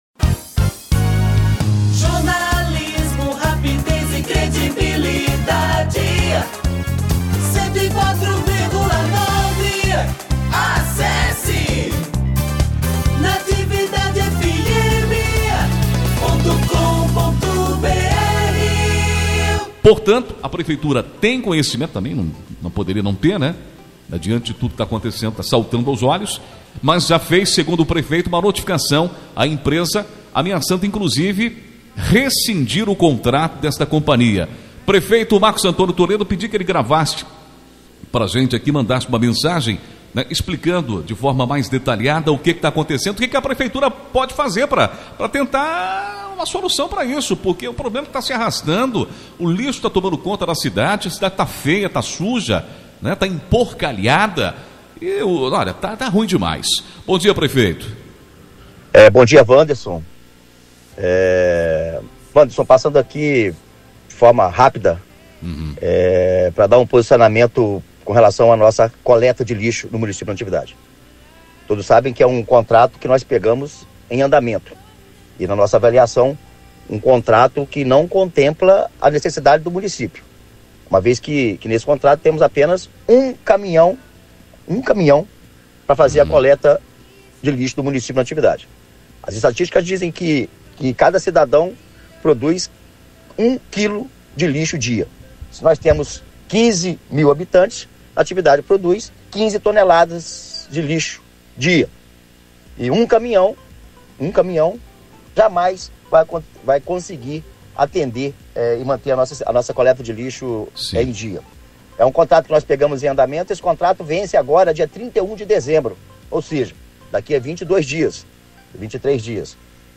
Em declaração à Rádio Natividade (OUÇA ABAIXO), Toledo alegou que o contrato, assumido em andamento pela atual gestão, era deficiente por prever apenas um caminhão para a coleta de todo o município.